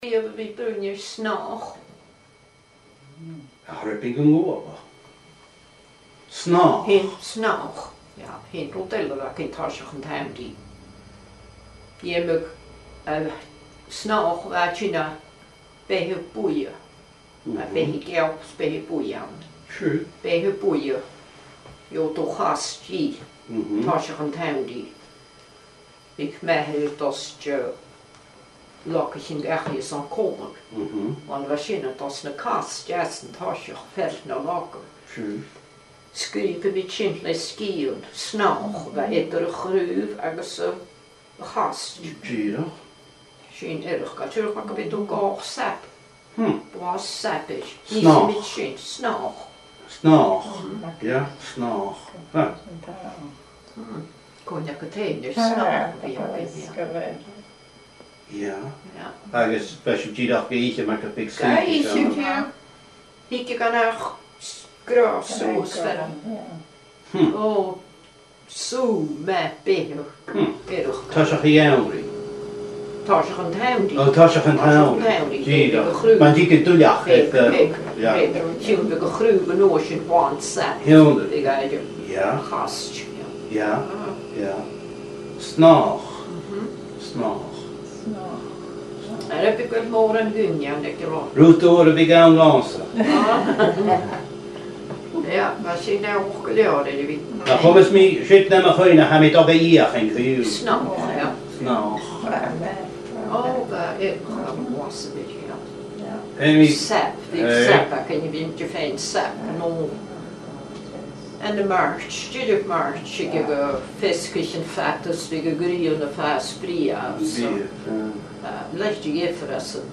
Fear-agallaimh